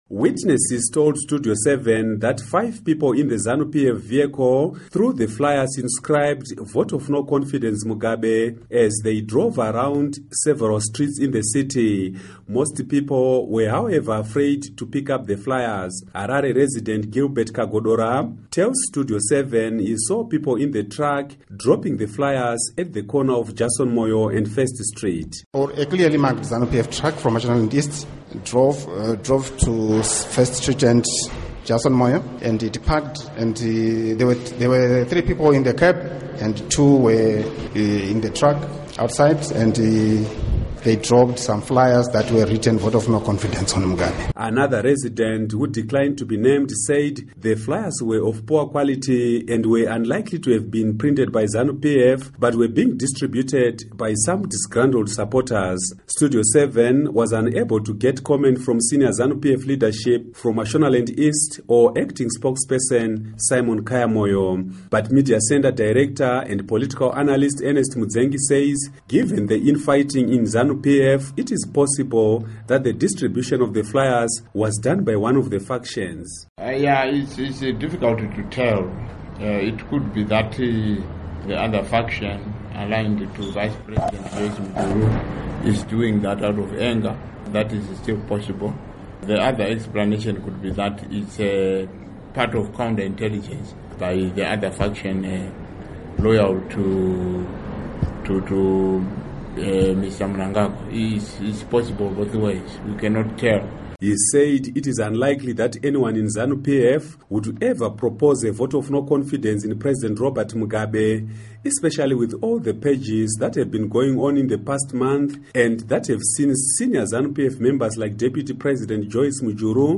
Report on Zanu PF Congress